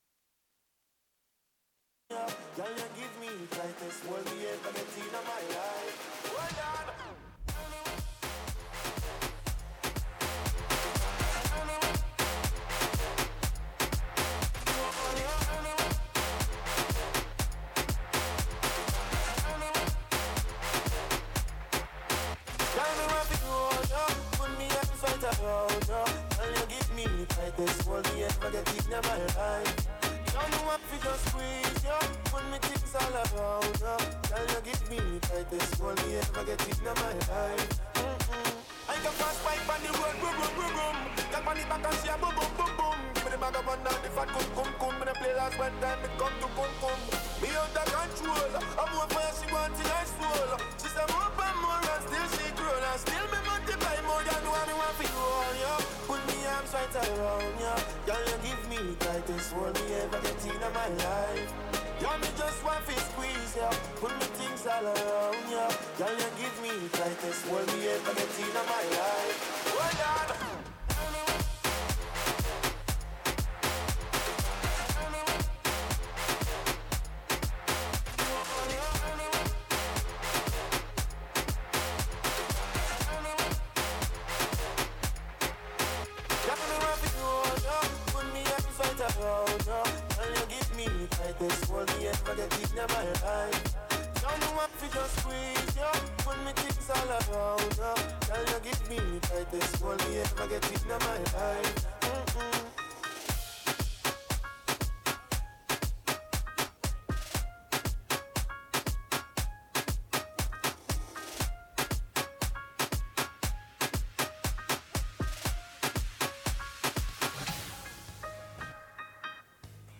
Ryan Walters Purdue head football coach On 2024 Team Outlook July 2024.mp3 (INTERVIEW STARTS AT 2:45 mark)